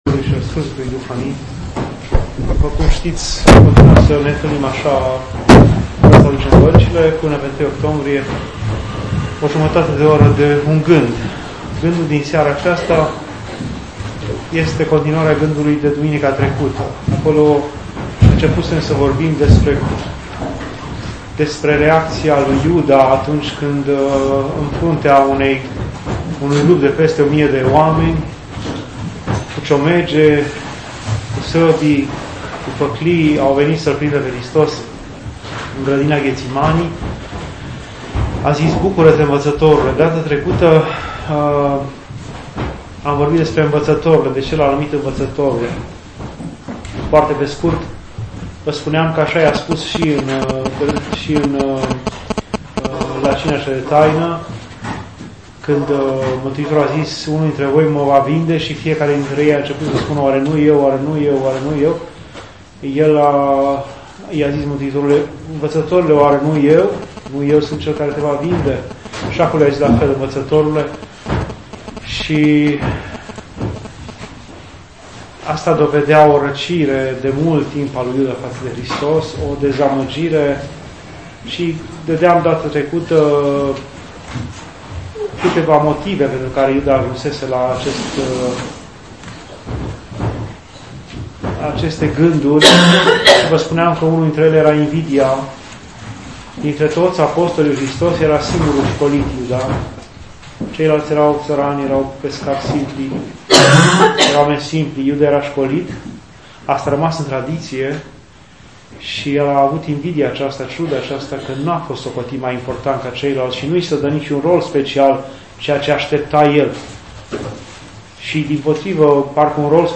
Aici puteți asculta și descărca înregistrări doar de la Bisericuța din Hașdeu